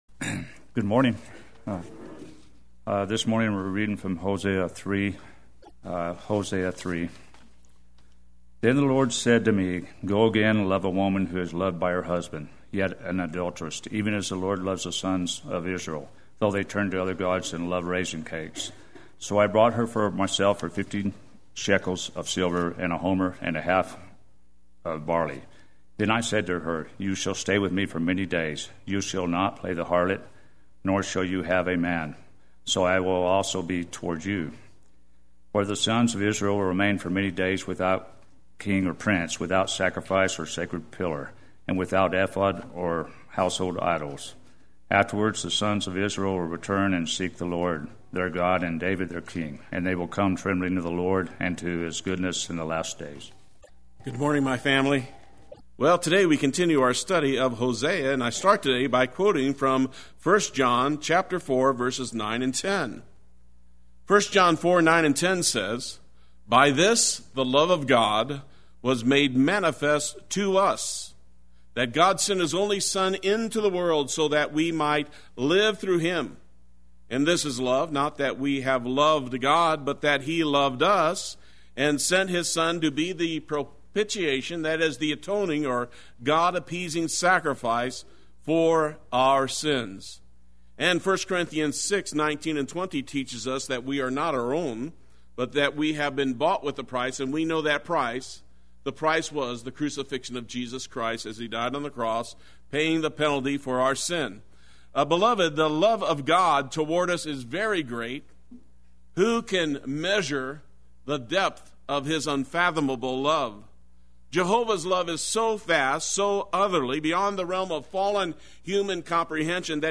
Play Sermon Get HCF Teaching Automatically.